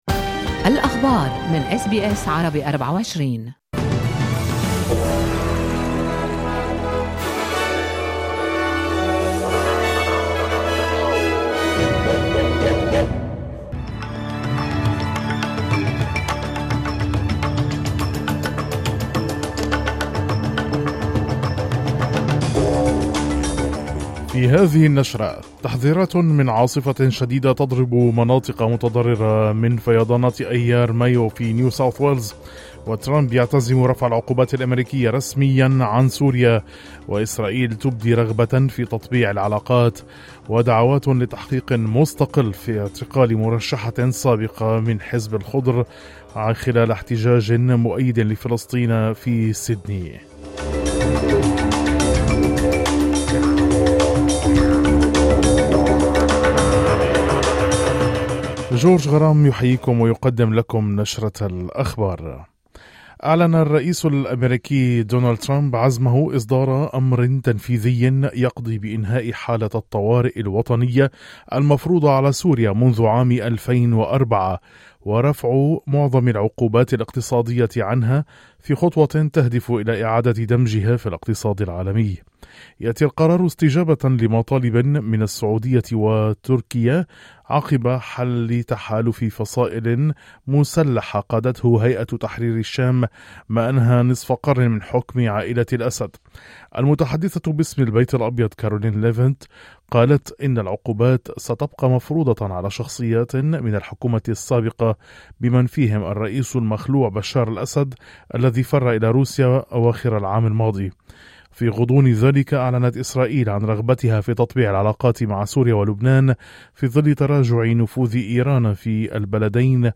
نشرة أخبار الصباح 01/07/2025